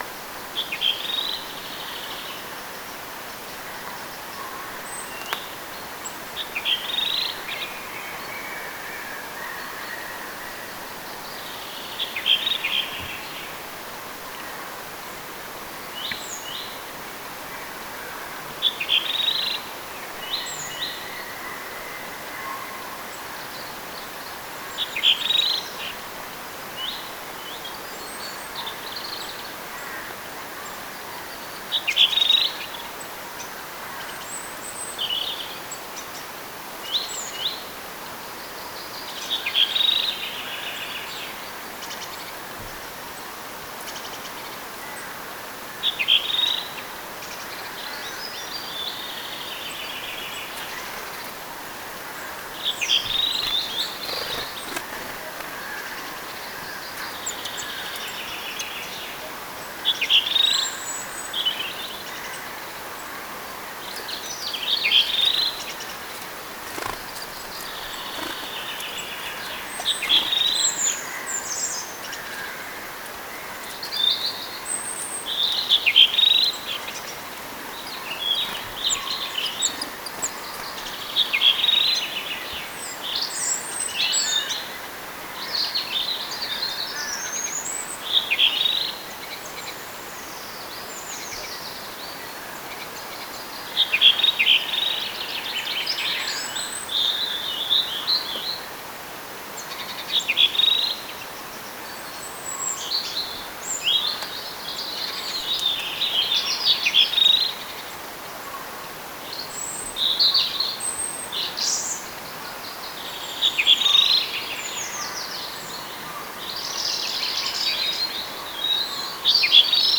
punakylkirastaslintu laulaa tuolla tavoin
säkeen väliin aina jonkinlaisen lyhyen pikkusäkeen,
joka on eri tyyppiä kuin sen oikea laulu.
punakylkirastaslintu_laulaa_valilla_vetaisee_ainoastaan_yhden_erilaisen_sakeen_valiin.mp3